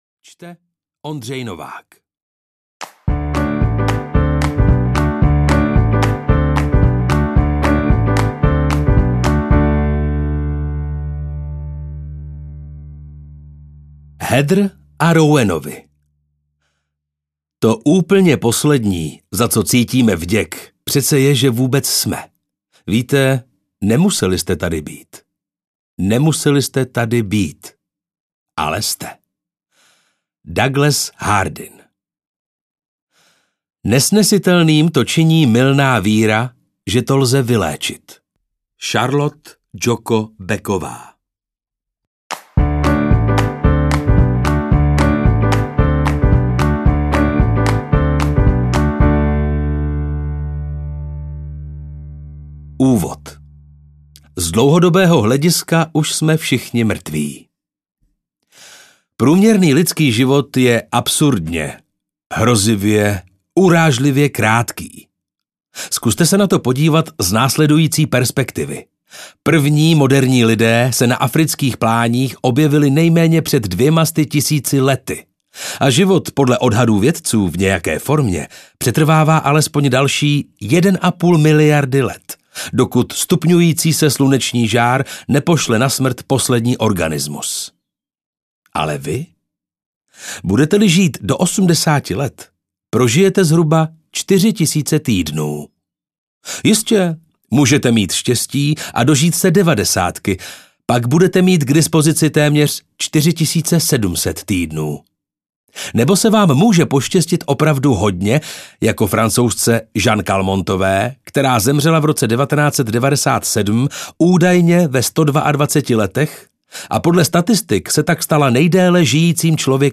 Čtyři tisíce týdnů audiokniha
Ukázka z knihy